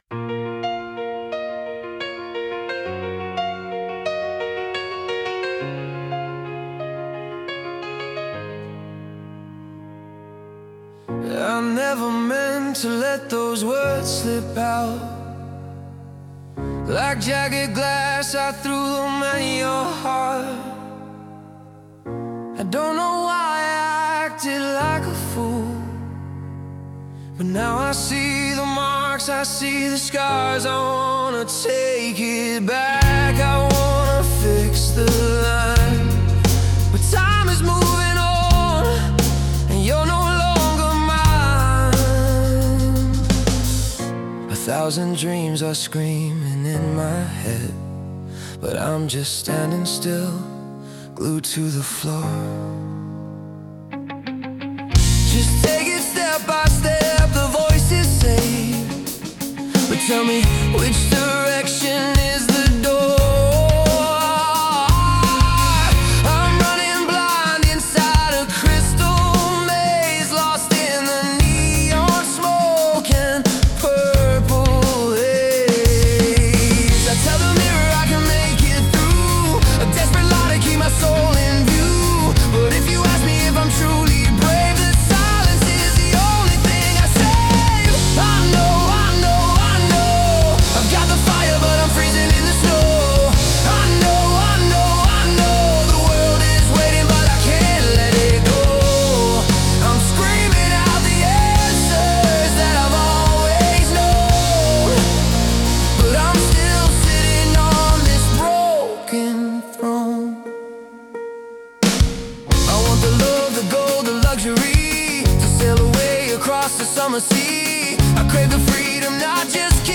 ＊この楽曲は有料版SNOW　AIで作成しました。